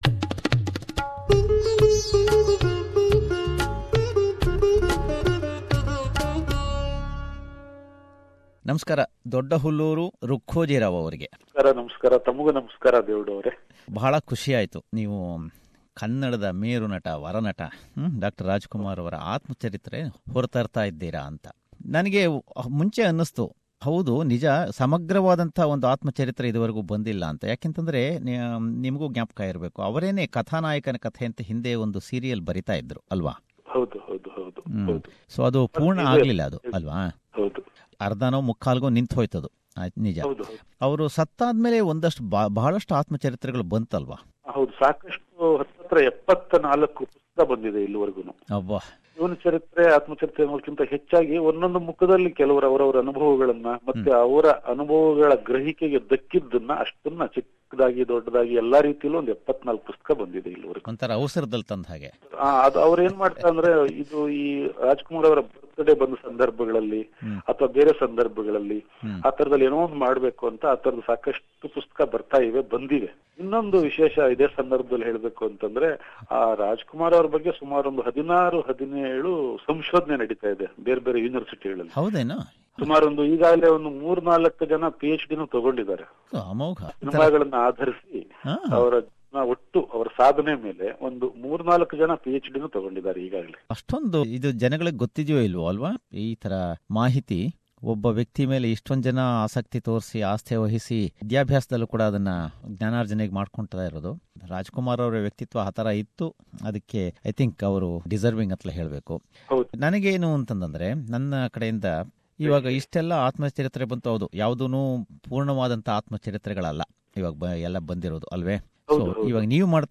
This is the first part of the interview with him.